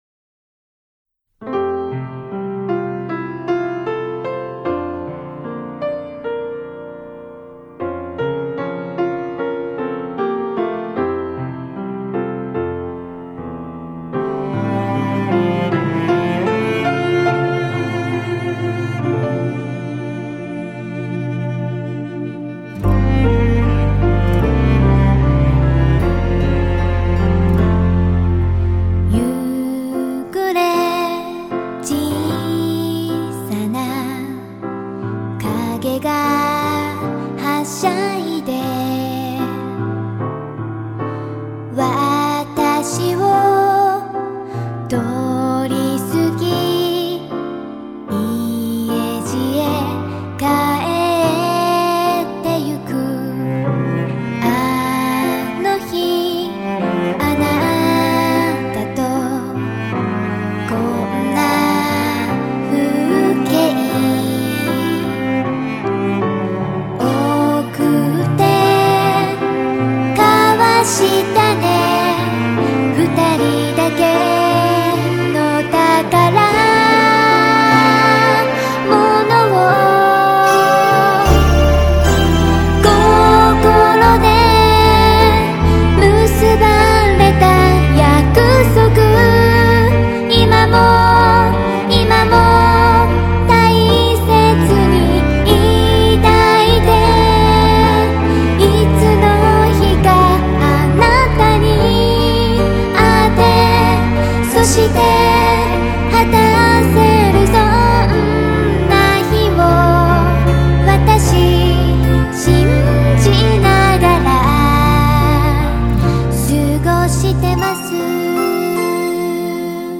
Overall: The audio of the mp3 is too low.